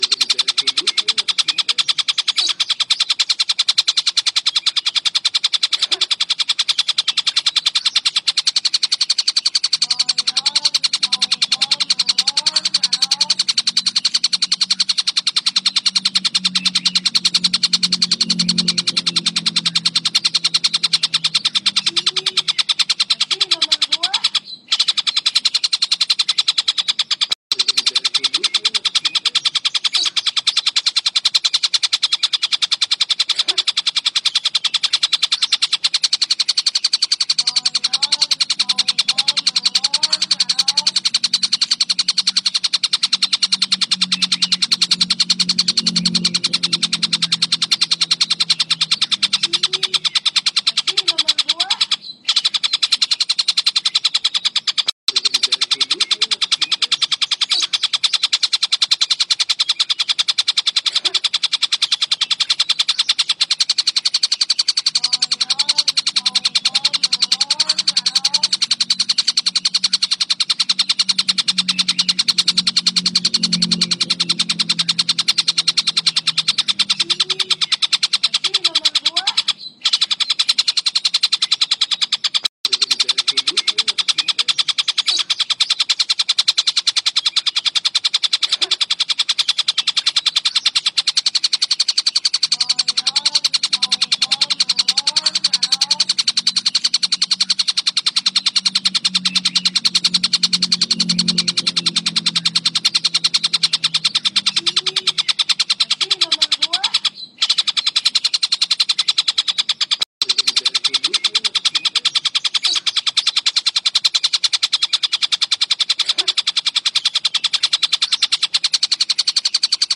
Cari suara burung Tengkek Buto jernih buat masteran?
Suara Burung Tengkek Buto
Tag: suara burung hias suara kicau burung suara Tengkek Buto
suara-burung-tengkek-buto-id-www_tiengdong_com.mp3